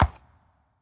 Minecraft Version Minecraft Version latest Latest Release | Latest Snapshot latest / assets / minecraft / sounds / mob / horse / skeleton / water / soft5.ogg Compare With Compare With Latest Release | Latest Snapshot